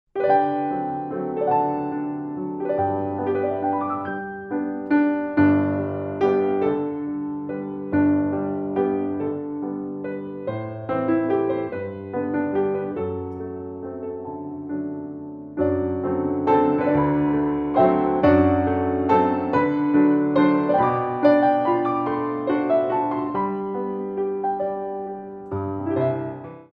Ballet Music for All Level Classes
Solo Piano
Moderate Waltzes